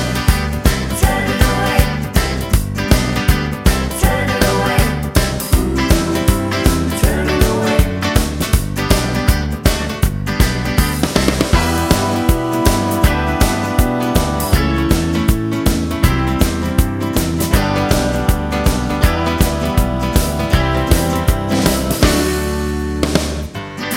No Lead Guitar Pop (1980s) 3:18 Buy £1.50